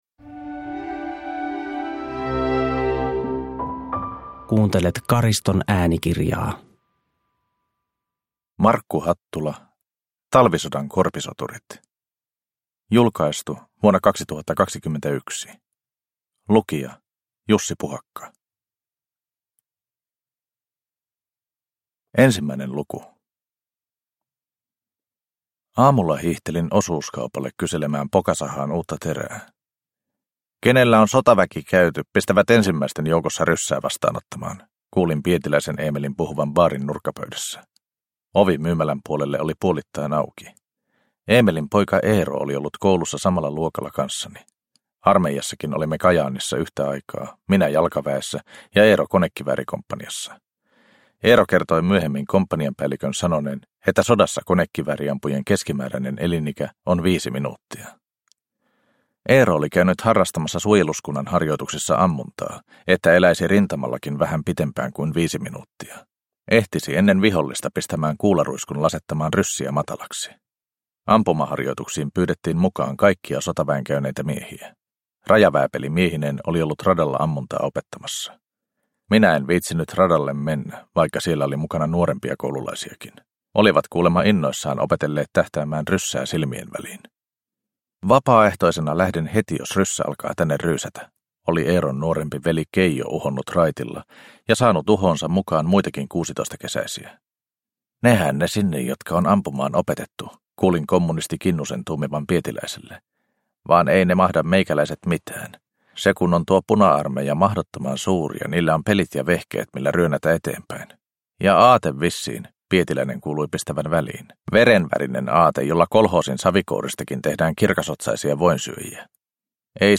Talvisodan korpisoturit – Ljudbok – Laddas ner